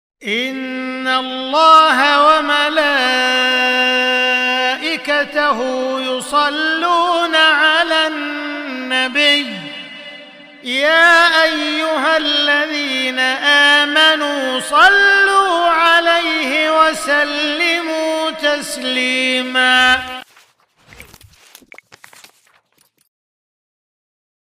تلاوات منوعة
3. مصحف الشيخ عبدالرحمن السديس ( تسجيل استديو رئاسة الحرمين )